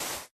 Sound / Minecraft / dig / sand3.ogg
sand3.ogg